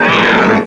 1 channel
rinoroar.wav